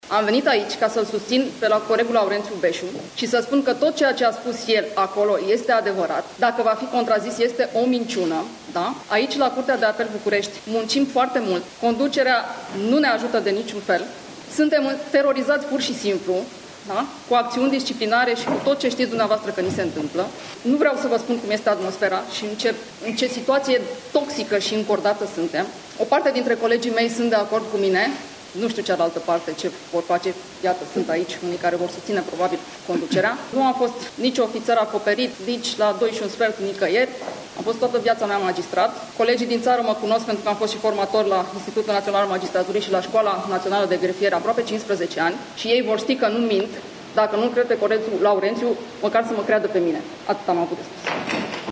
Declarațiile au fost făcute la începutul unei conferinţe extraordinare de presă organizate de Curtea de Apel București, la aproape două zile de la apariția documentarului Recorder.